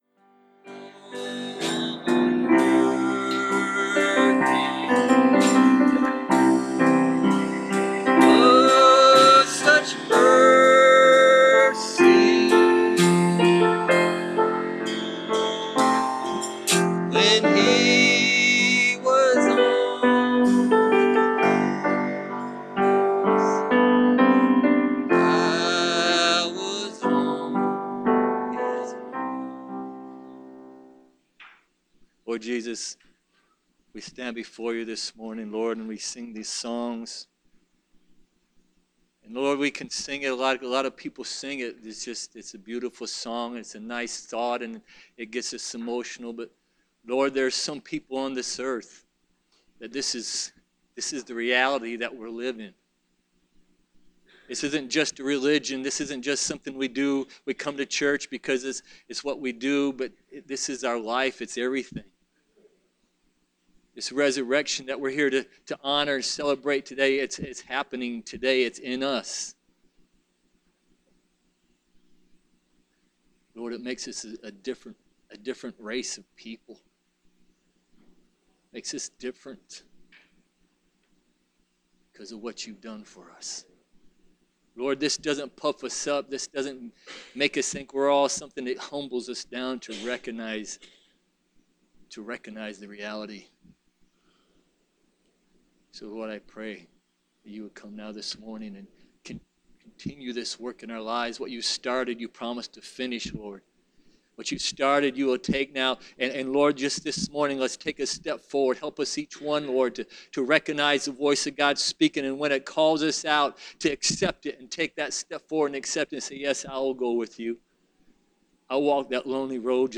Preached April 1, 2018